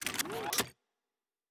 pgs/Assets/Audio/Sci-Fi Sounds/Weapons/Weapon 09 Reload 2.wav at master
Weapon 09 Reload 2.wav